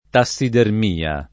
tassidermia [ ta SS iderm & a ] s. f.